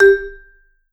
Index of /90_sSampleCDs/Sampleheads - Dave Samuels Marimba & Vibes/VIBE CMB 2C